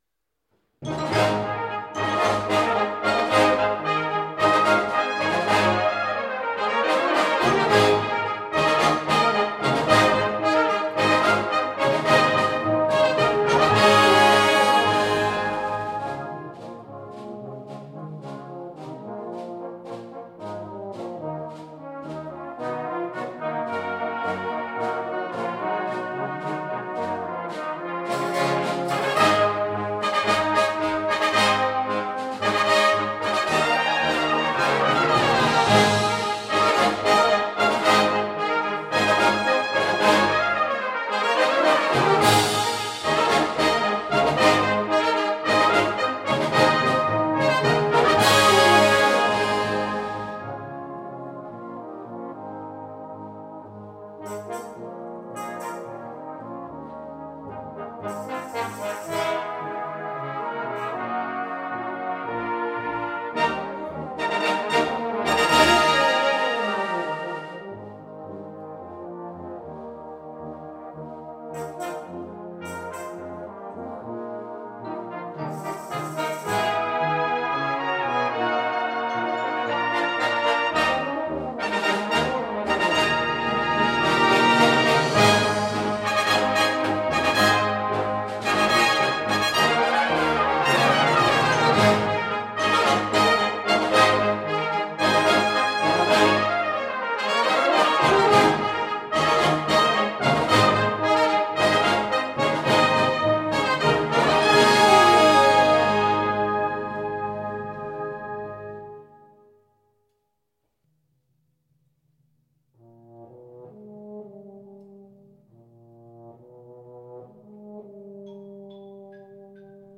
For Brass Band